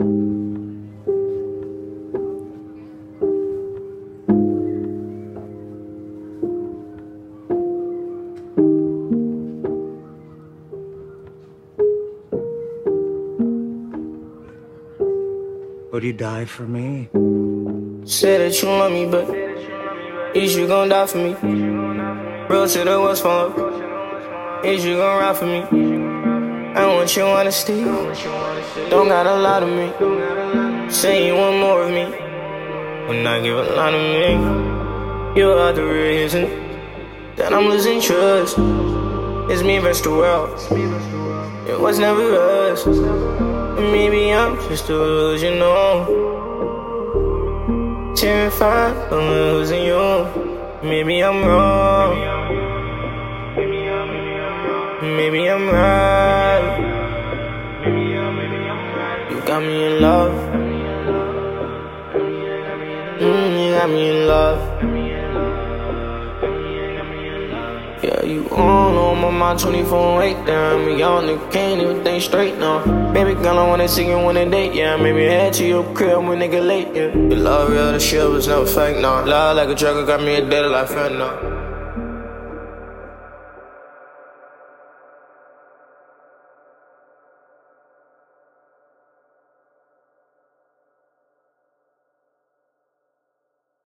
A snippet I made of someone I used to love ❤ it’s not mixed might sound a little iffy. Earbuds are better. 10 months ago Still Need Help?